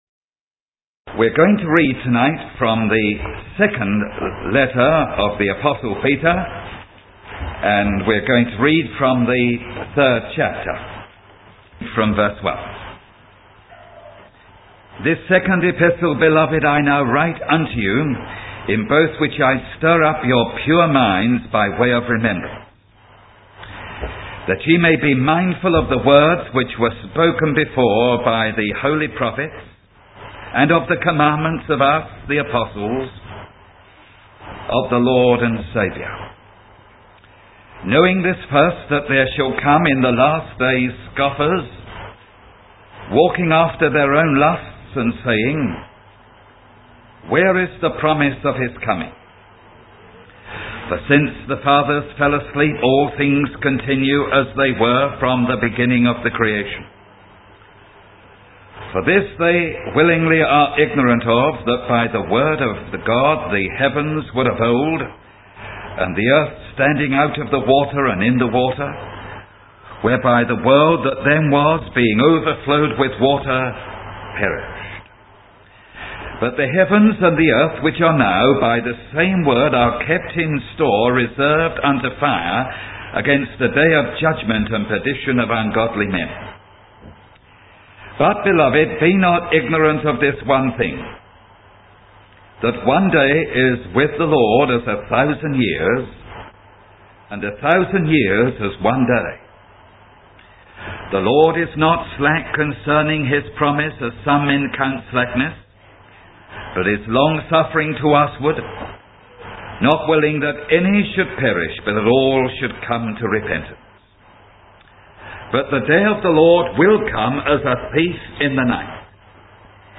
The sermon serves as a loving reminder of the importance of responding to God's persistent call to repentance and faith.